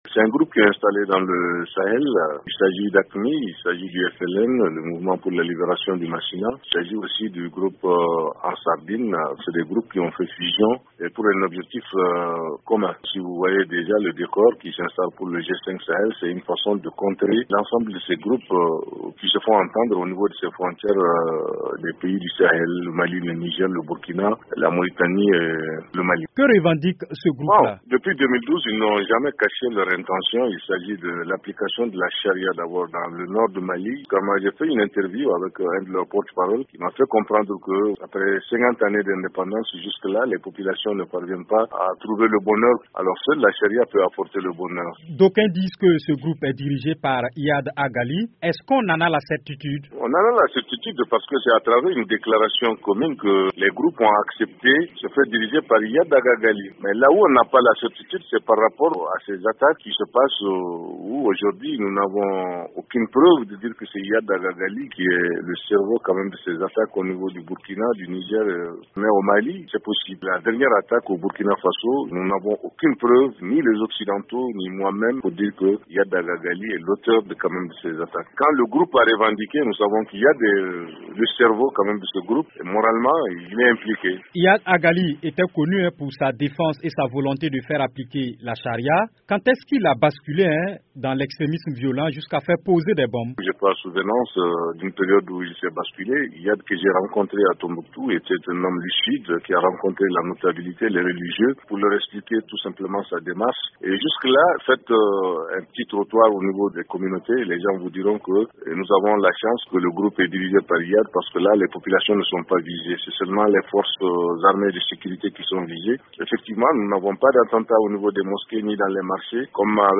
journaliste à Tombouctou joint par